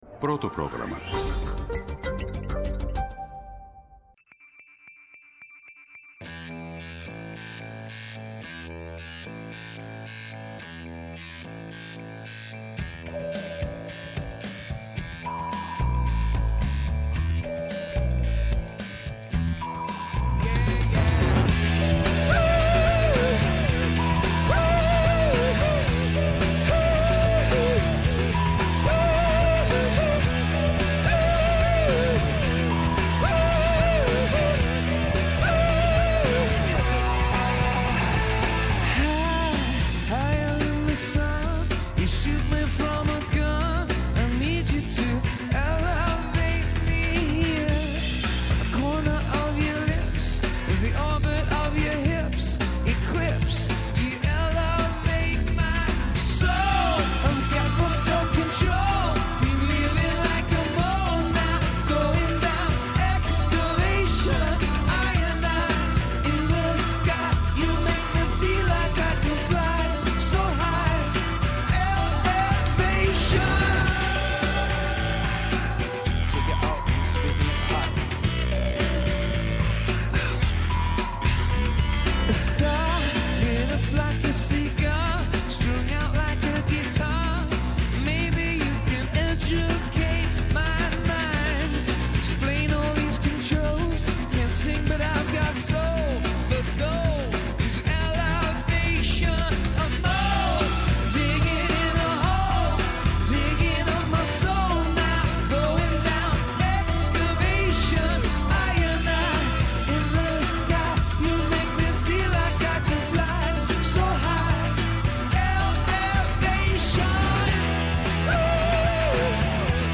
Ένα δίωρο ενημέρωσης με ουσία και πολλή καλή μουσική. ΕΡΤNEWS RADIO Πρωτο